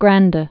(grăndə, gräɴdĭ)